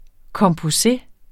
Udtale [ kʌmpoˈse ]